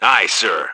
H_soldier1_28.wav